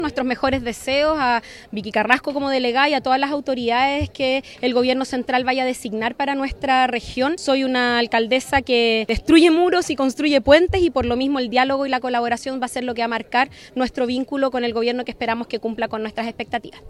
La alcaldesa de Valdivia y militante del Frente Amplio, Carla Amtmann, le deseó lo mejor a Vicky Carrasco, recalcando que el diálogo marcará su relación con el Gobierno entrante.
alcaldesa-valdivia-nueva-delegada.mp3